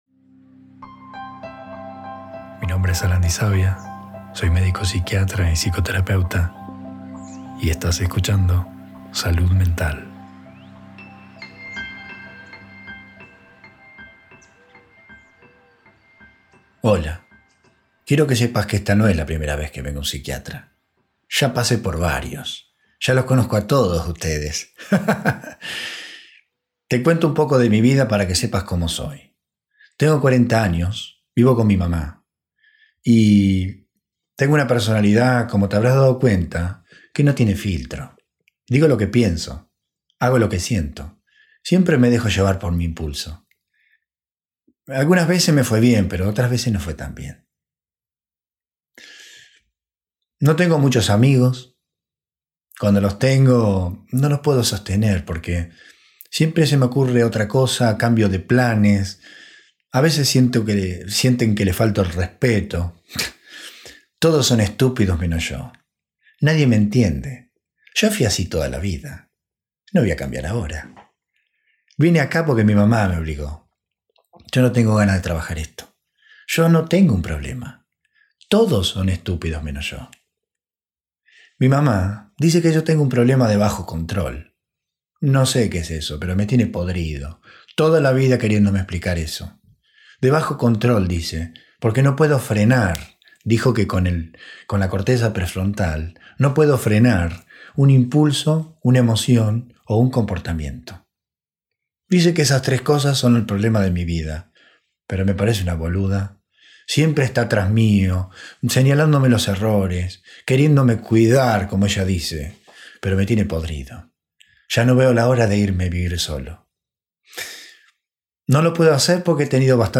Dramatización del perfil de Bajo Control subtipo excesivamente desagradable